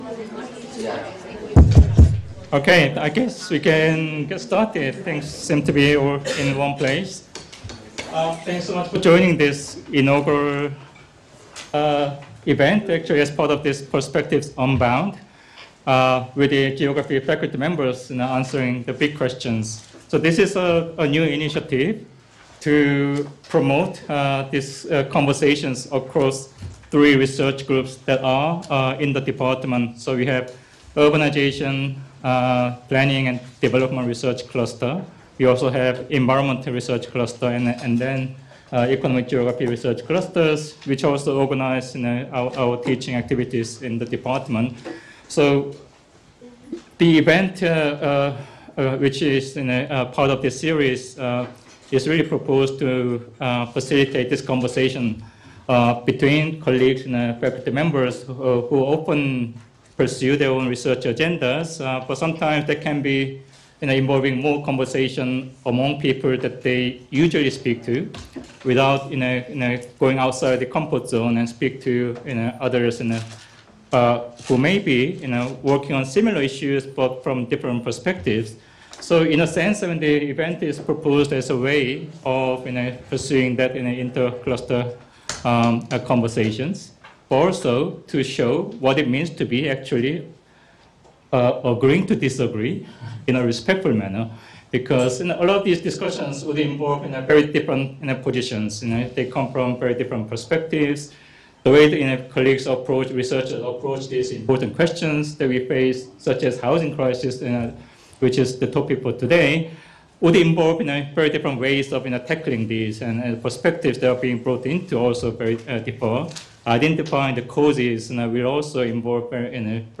Each seminar will feature a roundtable discussion where faculty share insights, challenge perspectives and explore connections across their fields.